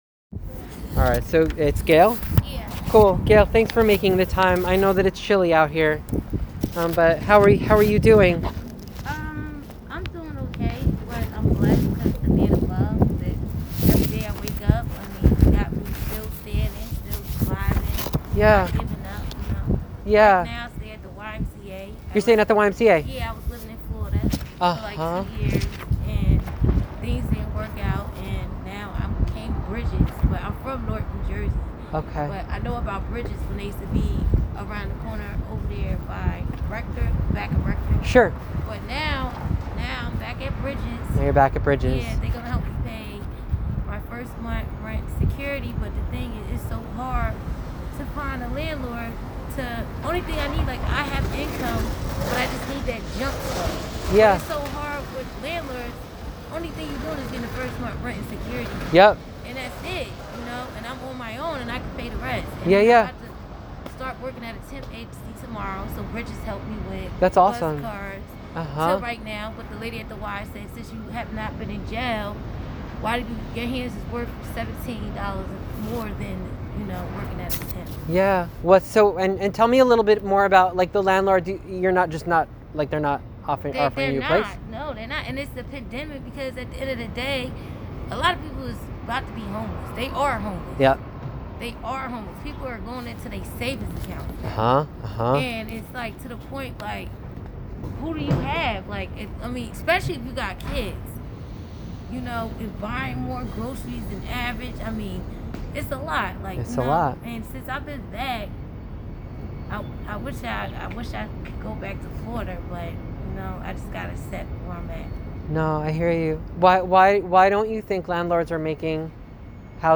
Participant 195 Community Conversations Interview